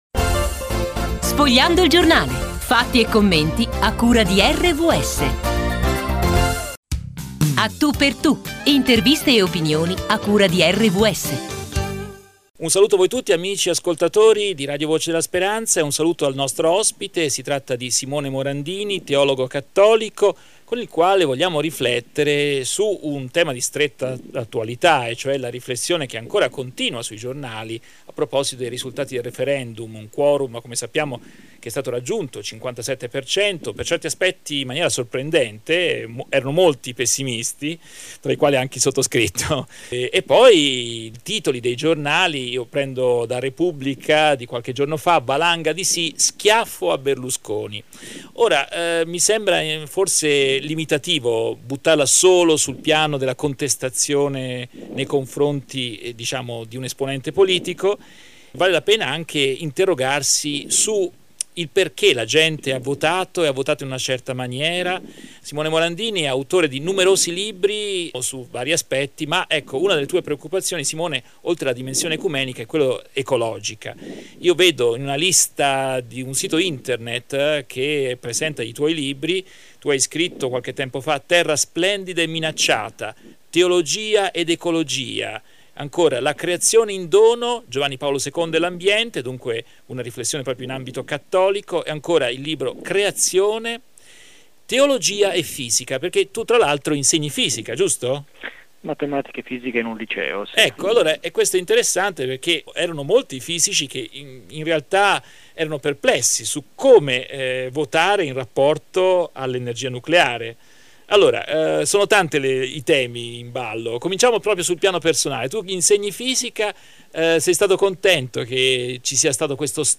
I risultati del referendum su acqua e nucleare commentati da un teologo cattolico autore di numerosi libri sulla relazione fede e impegno per il creato. Intervista